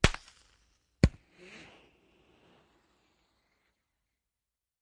Smoke Grenade: Pop And Ignite Close-up. Stereo